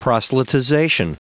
Prononciation du mot proselytization en anglais (fichier audio)
Prononciation du mot : proselytization